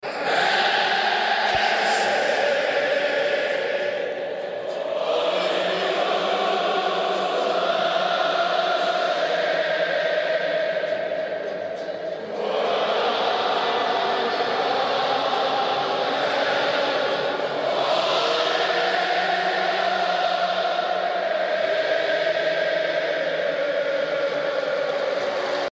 SUNDERLAND FANS SINGING ELVIS PRESLEY sound effects free download
This is from May 24th at Wembley. Fantastic to hear the atmosphere and Elvis blending together.